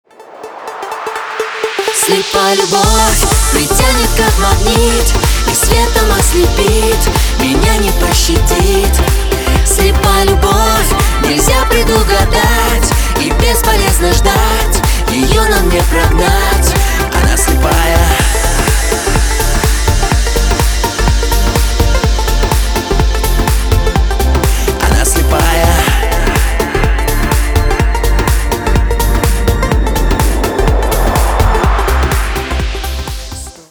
• Качество: 320, Stereo
громкие
дуэт
попса
мужской и женский вокал